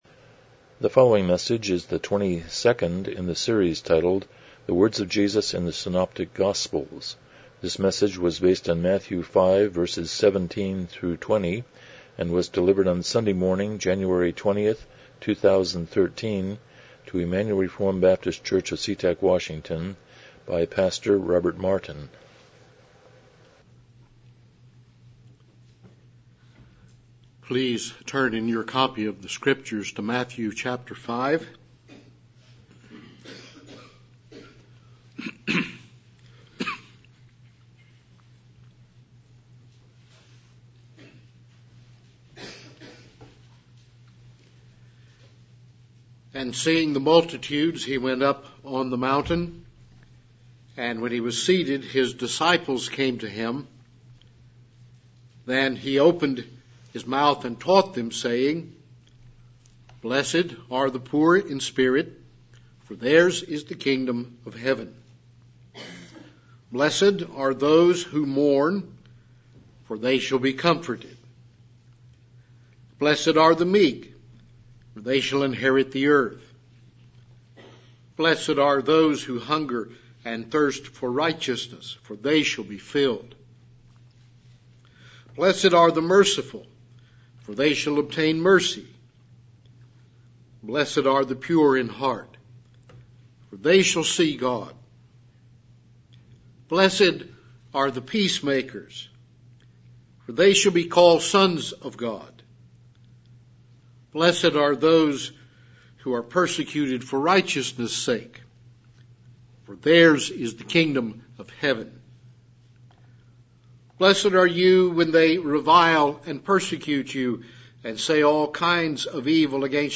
Passage: Matthew 5:17-20 Service Type: Morning Worship